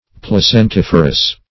Search Result for " placentiferous" : The Collaborative International Dictionary of English v.0.48: Placentiferous \Plac`en*tif"er*ous\, a. [Placenta + -ferous.]